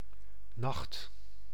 Ääntäminen
IPA : /ˈnaɪt/